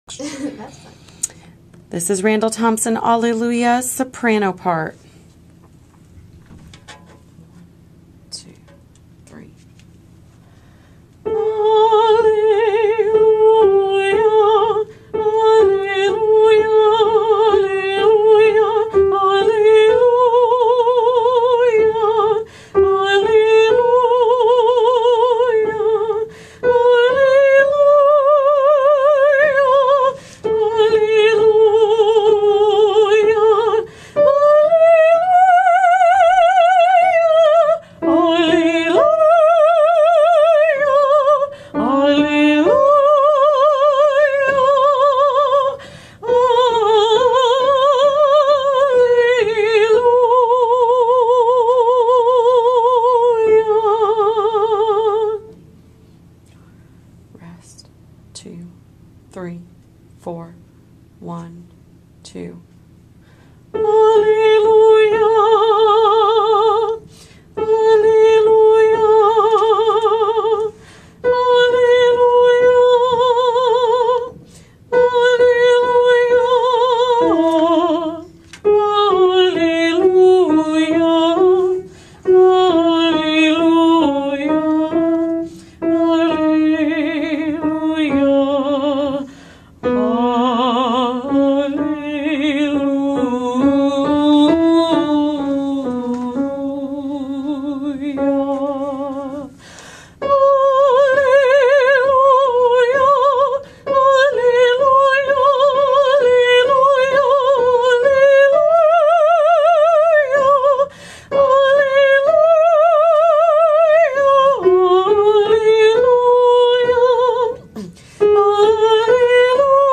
MP3 versions chantées
Soprano Part